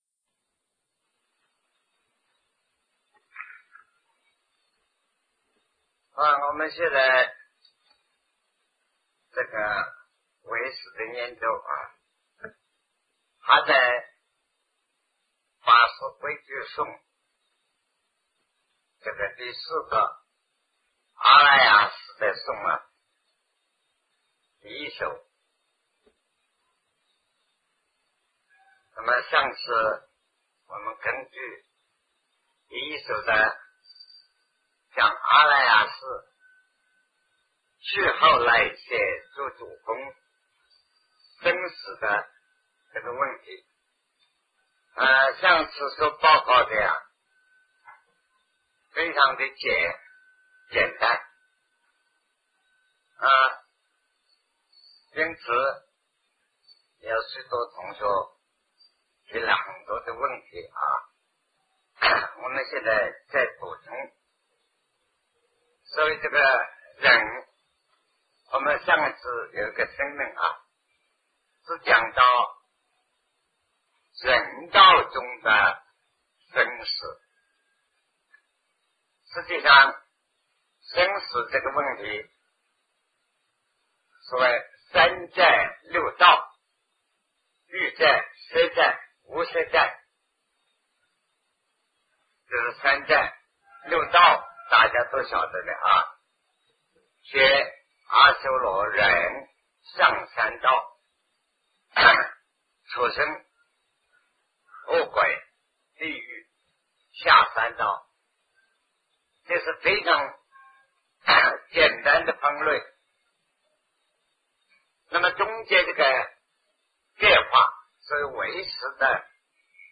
人投生的情形 南师讲唯识与中观（1980代初于台湾013(上)